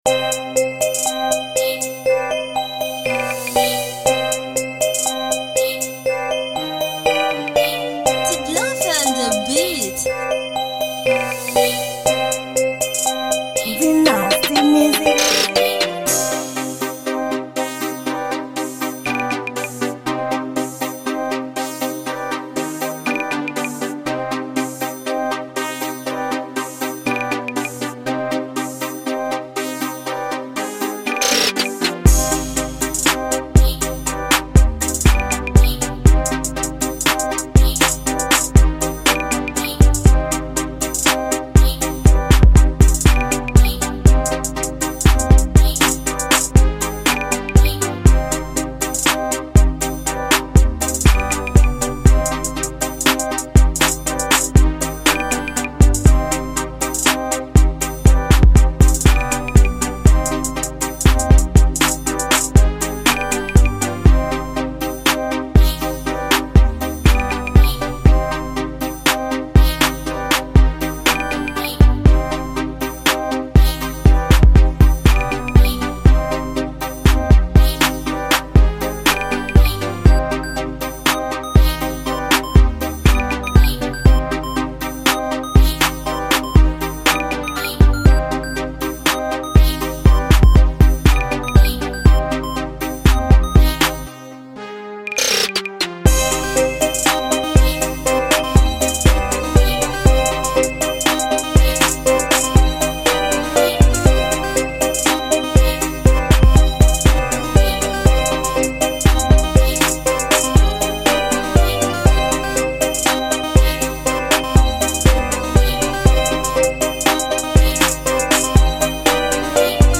Genre: Beat.